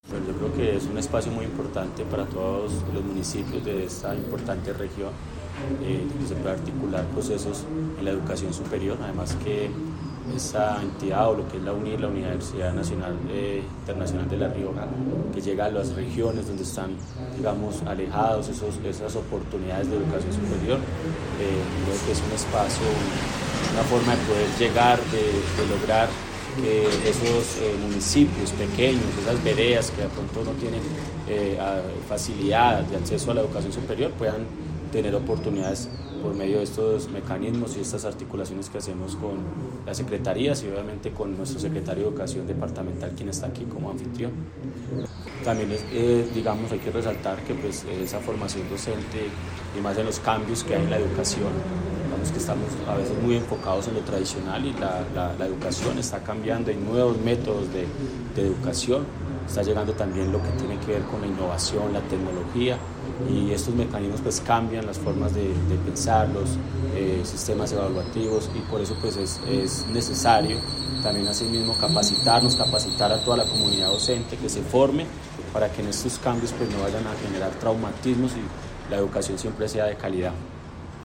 Director de Educación de La Dorada, Juan Camilo Aldana.
Juan-Camilo-Aldana-director-de-Educacion-de-La-Dorada.mp3